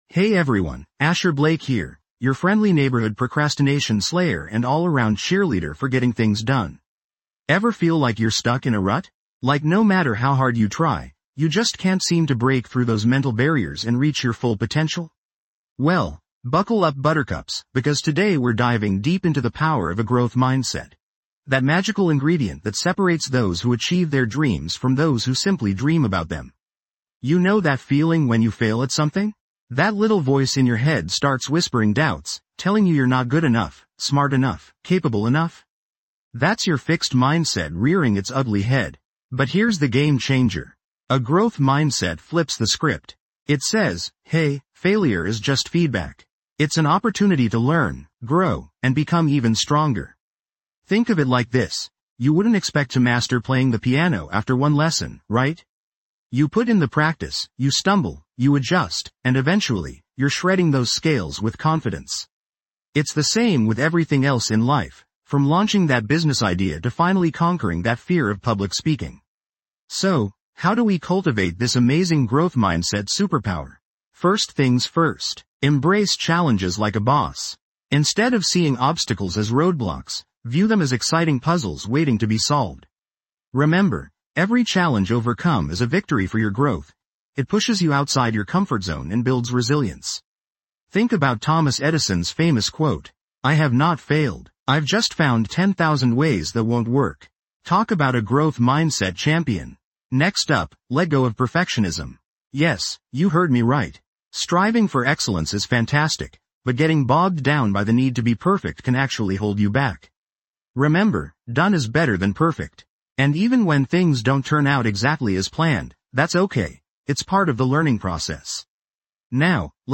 Podcast Category:. Self-improvement, Personal Development, Productivity, Motivational Talks Subscribe to Procrastination Killer Get Things Done, and Share the podcast with your friends, and bring more mindfulness, energy, and positivity into your life every day.
This podcast is created with the help of advanced AI to deliver thoughtful affirmations and positive messages just for you.